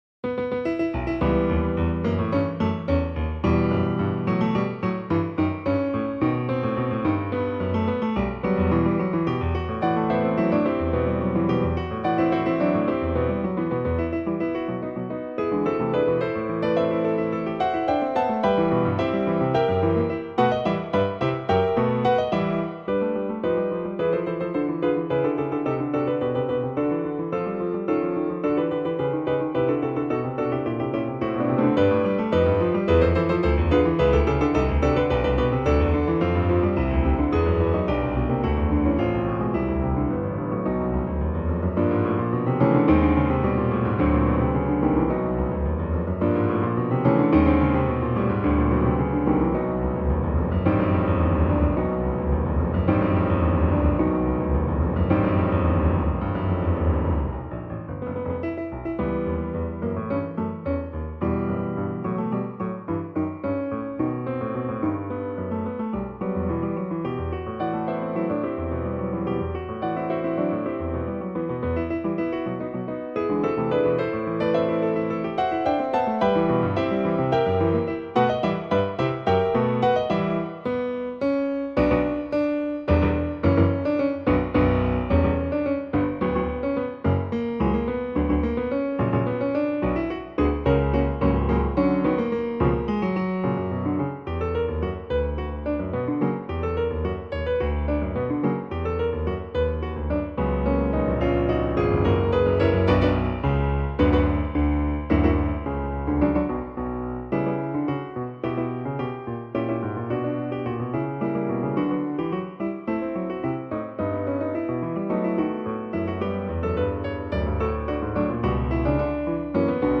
クラシックピアノから私のお気に入りをmidiで打ち込んでみました。
題名の通り、渋くカッコ良いマーチ風の雰囲気が特徴です。